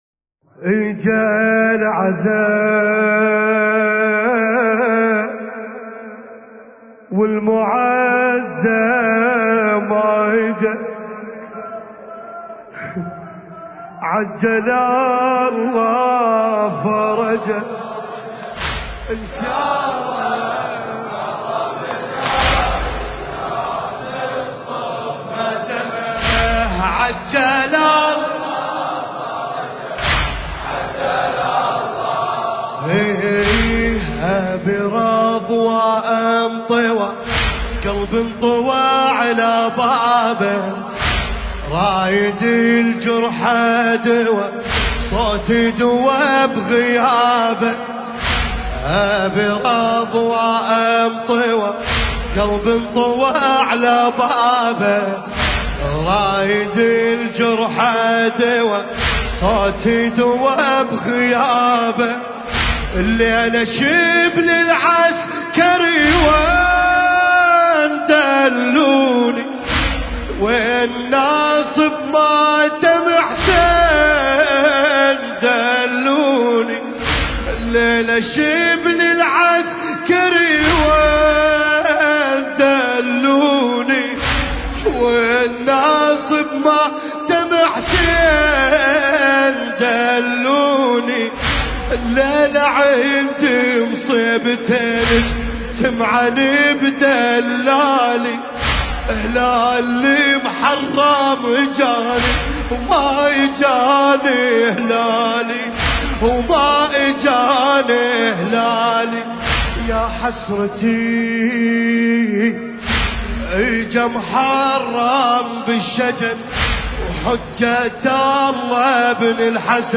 المكان: حسينية الإمامين الكاظمين/ دولة الكويت
ليلة ١ محرم ١٤٤١ للهجرة